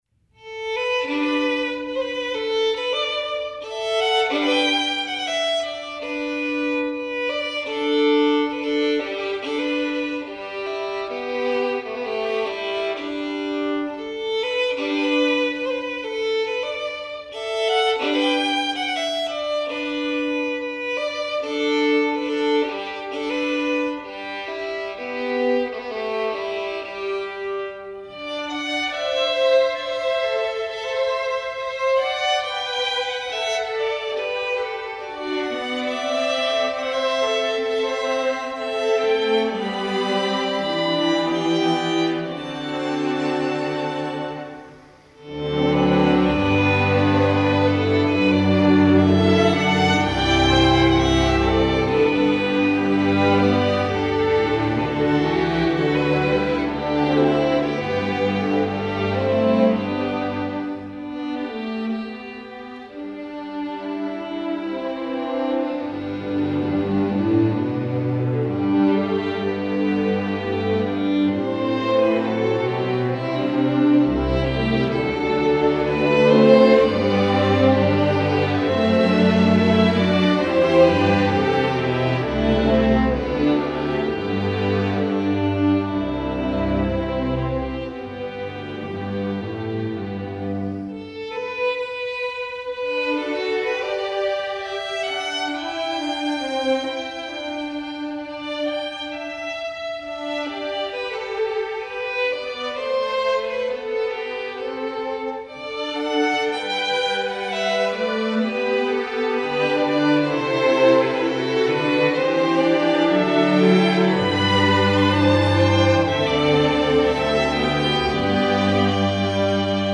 Voicing: String Orchestra